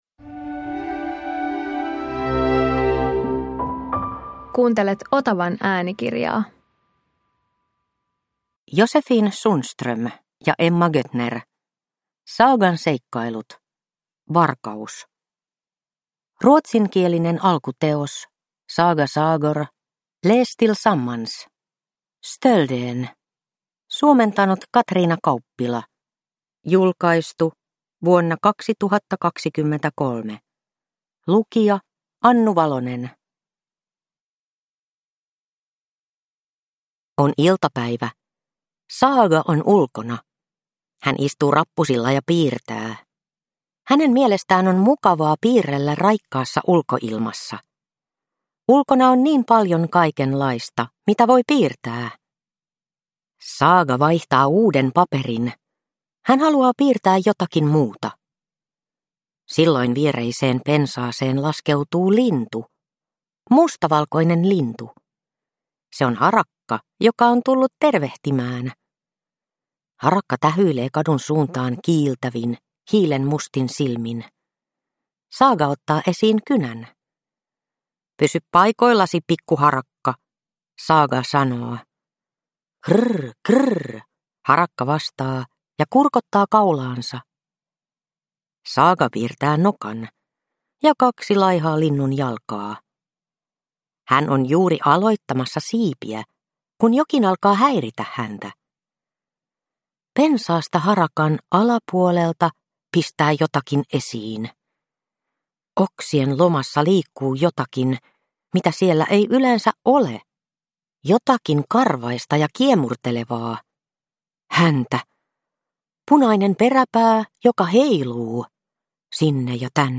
Saagan seikkailut. Varkaus – Ljudbok